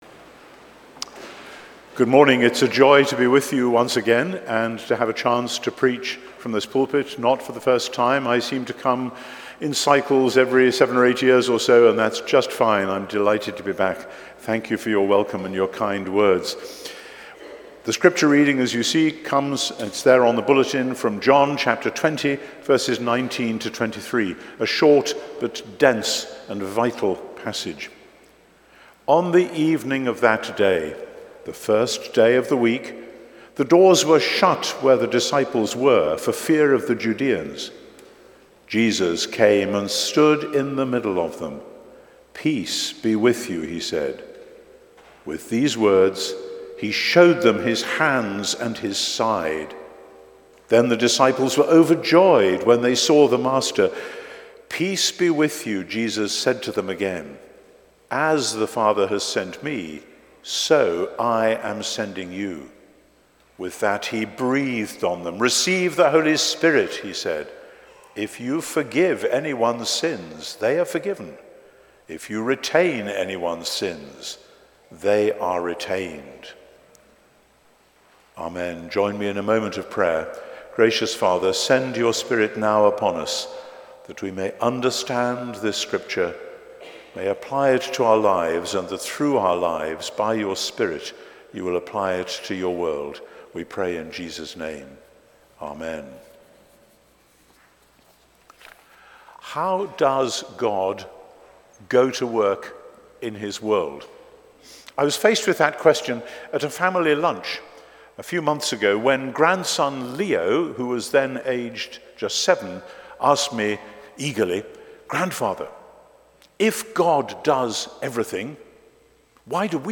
But upon closer examination of a short but dense passage in John 20, we can see with beautiful clarity that Jesus creates anew through his Spirit, and more particularly, through his people. Watch this sermon from the Right Reverend N.T. Wright as we consider the role we play in God’s dwelling in the midst of his world.